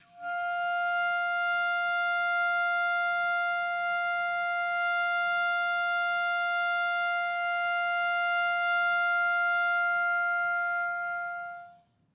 DCClar_susLong_F4_v2_rr1_sum.wav